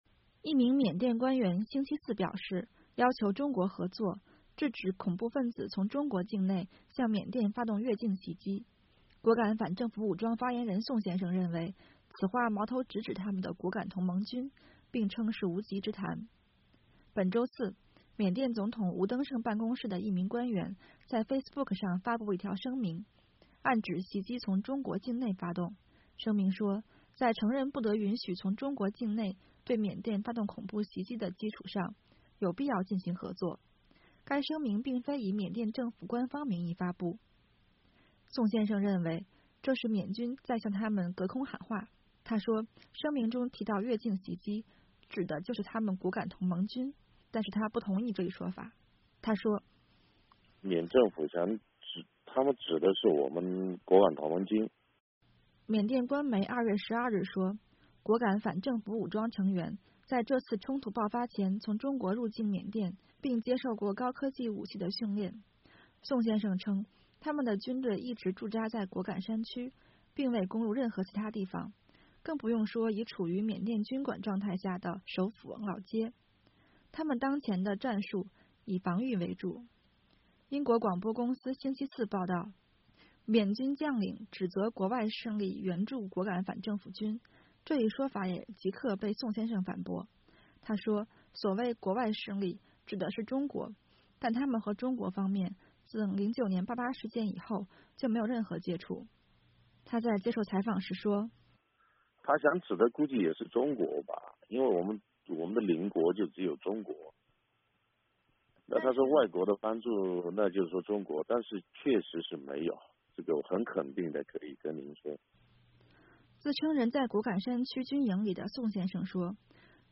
“缅政府他们指的是我们果敢同盟军，但是没有这回事。”他在接受美国之音电话采访时说。